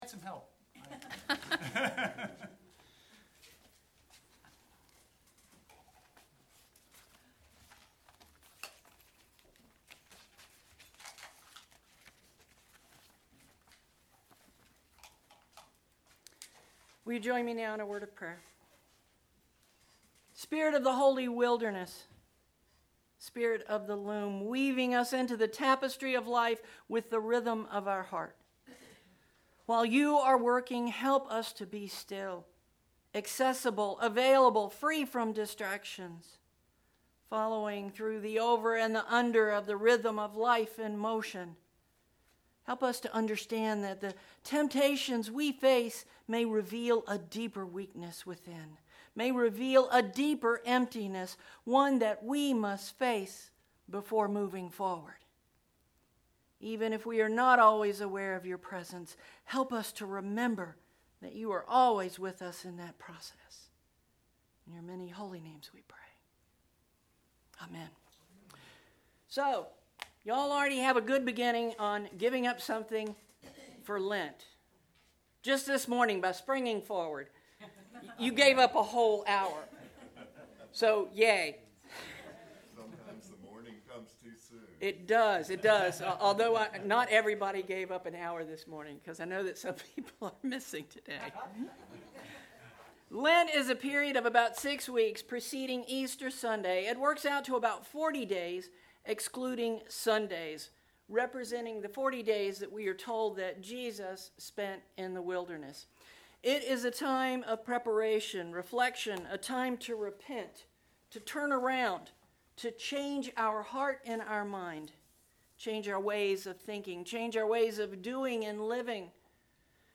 Click below to listen to the sermon from Sunday 03/10/19